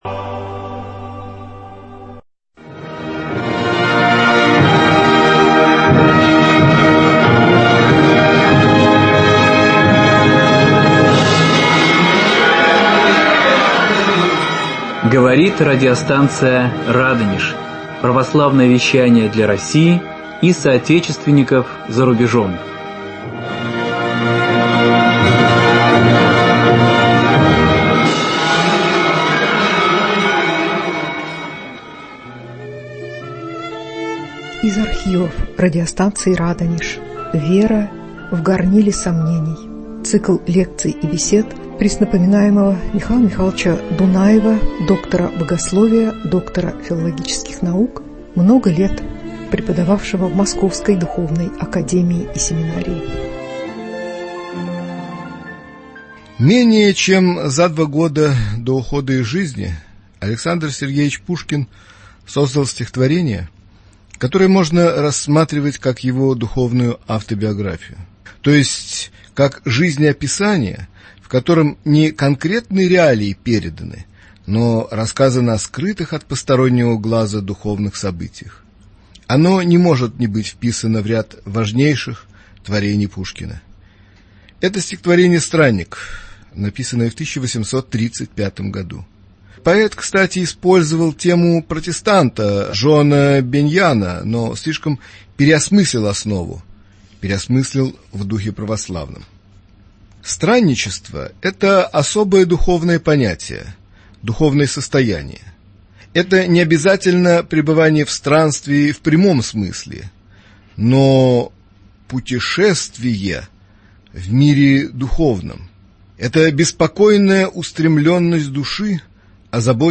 Из архивов радио.